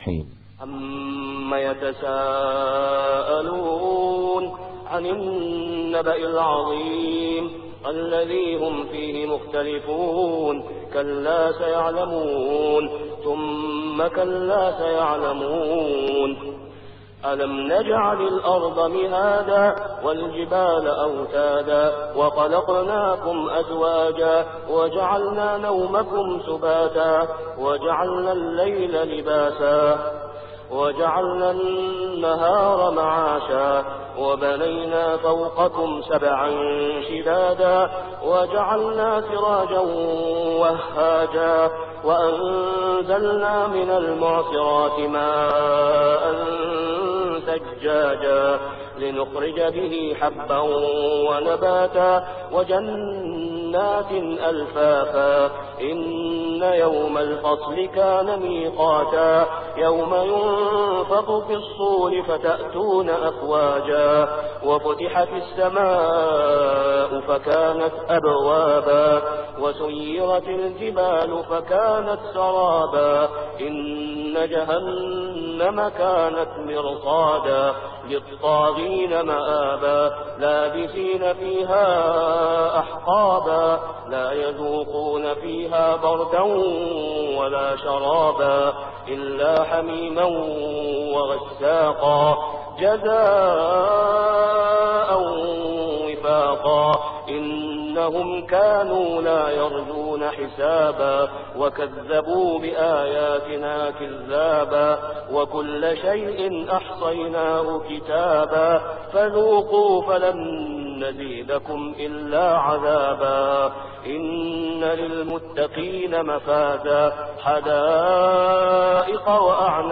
سورة النبإ > السور المكتملة للشيخ أسامة خياط من الحرم المكي 🕋 > السور المكتملة 🕋 > المزيد - تلاوات الحرمين